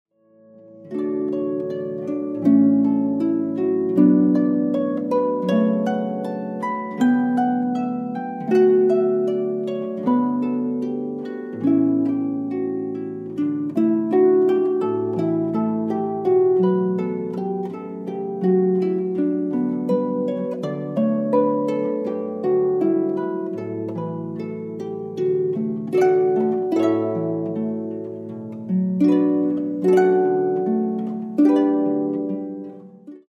This CD is a wonderful collection of harp music including